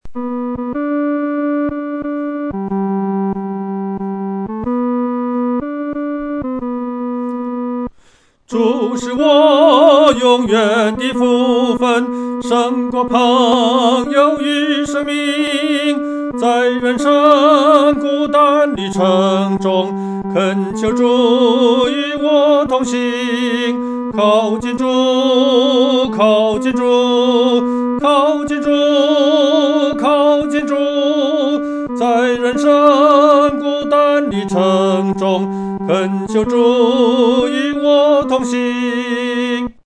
独唱（第三声）
靠近主-独唱（第三声）.mp3